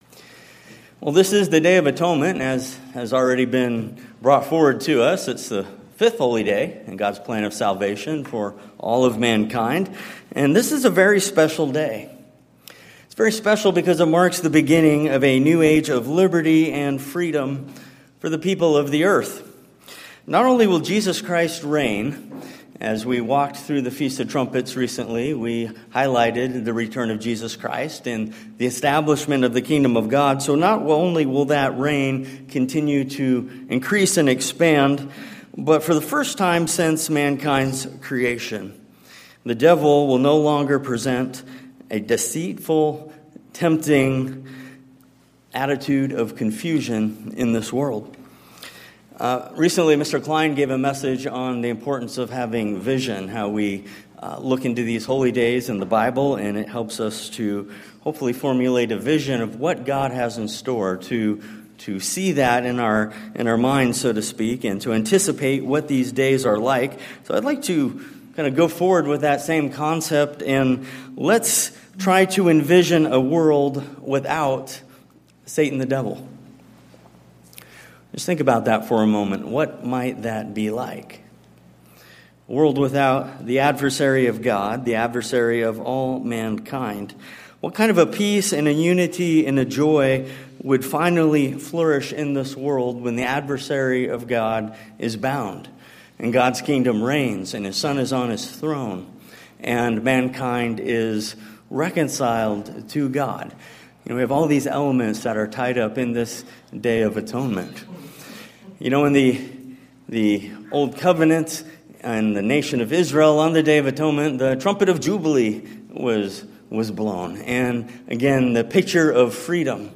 This sermon catches a glimpse of what that wonderful day will look like.